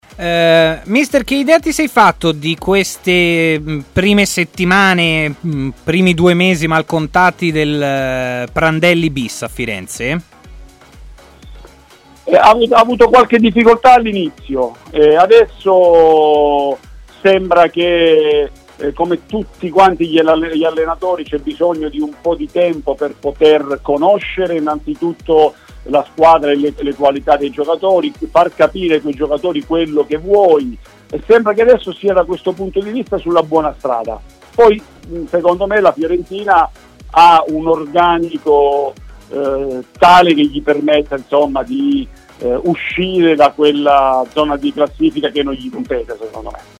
Giuseppe Pancaro, ex viola ai tempi di Prandelli, ha parlato brevemente della seconsda avventura del tecnico alla Fiorentina, durante il suo intervento a Stadio Aperto su TMW radio: "All'inizio ha avuto delle difficoltà, ma adesso mi sembra sulla buona strada. La Fiorentina ha un organico tale che gli permetterà di uscire da quella zona di classifica, che non gli compete".